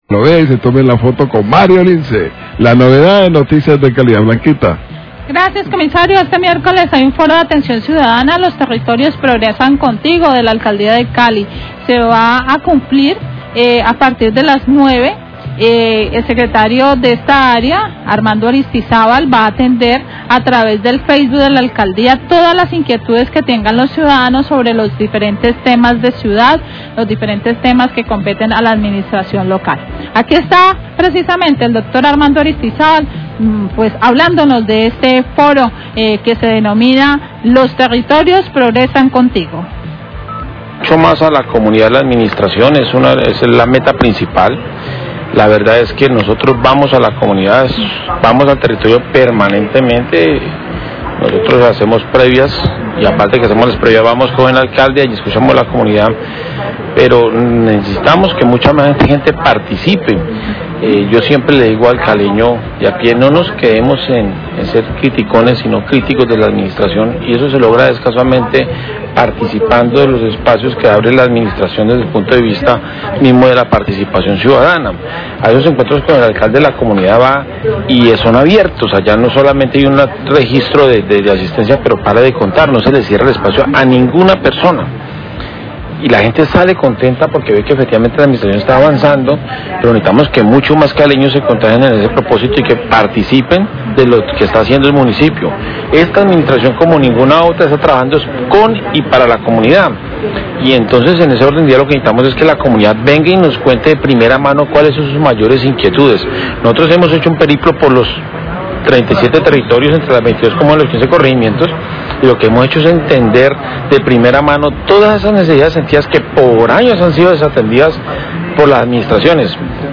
Radio
Informa que este miércoles a las 9am habrá un foro de atención ciudadana llamado: 'Los territorios progresan contigo', su objetivo es que la comunidad participe sobre las necesidades de la ciudad. Sobre esto habló el secretario de Desarrollo Territorial y Participación Ciudadana, Armando Aristizábal.